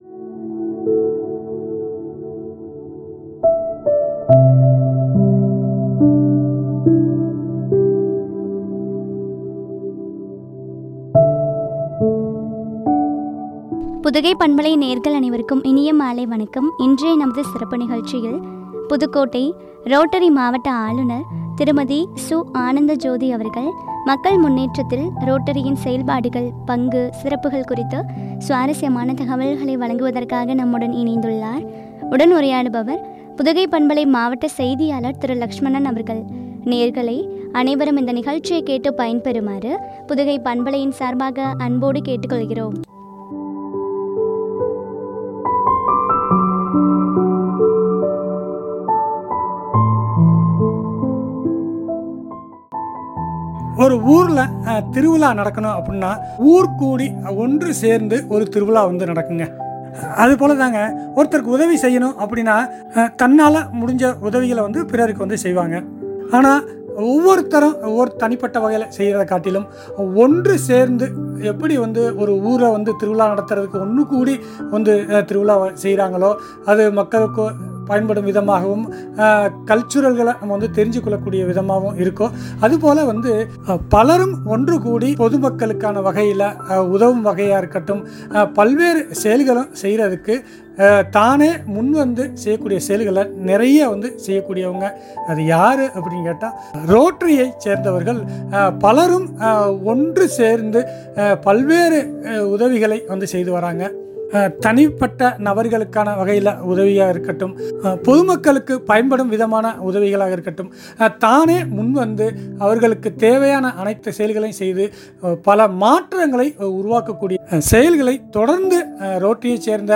” மக்கள் முன்னேற்றத்தில் ரோட்டரியின் பங்கு” குறித்து வழங்கிய உரையாடல்.